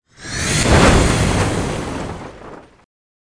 Death SFX
死亡音效